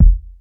07_Kick_01_SP.wav